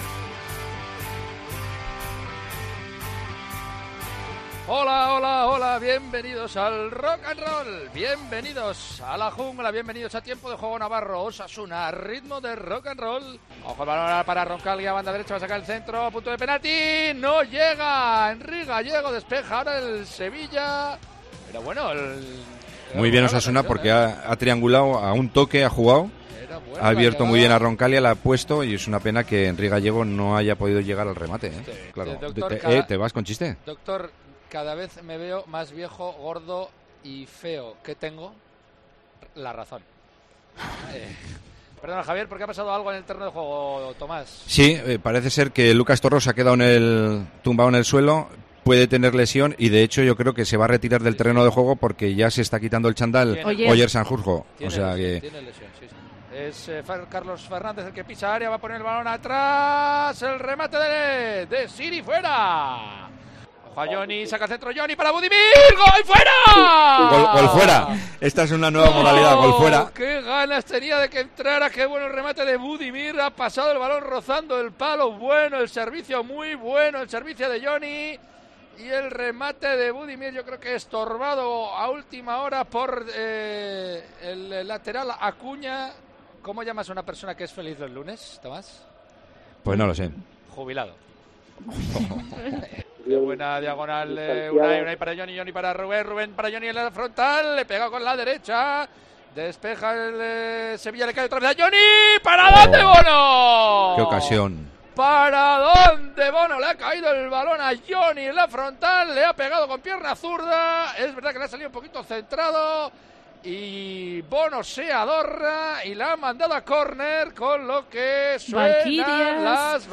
Resumen de la retransmisión de Tiempo de Juego Navarro a ritmo de Rock&Roll del partido entre Sevilla y Osasuna